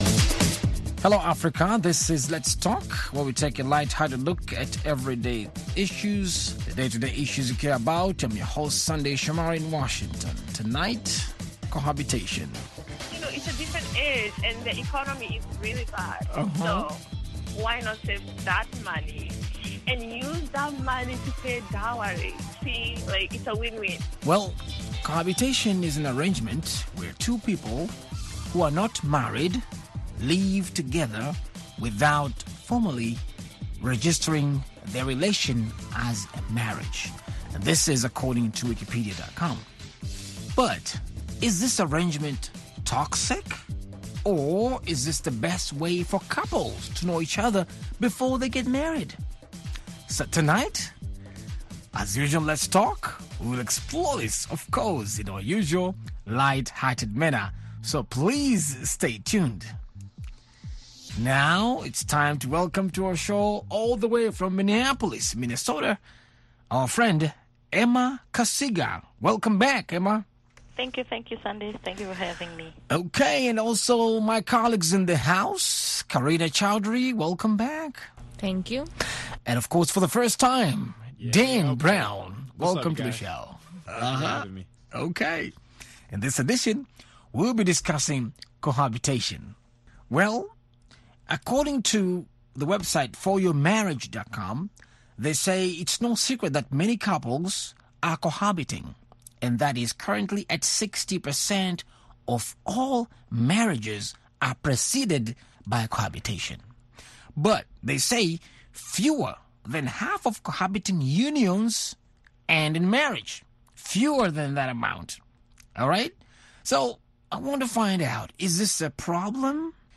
Let's Talk is an interactive discussion program about lifestyle issues.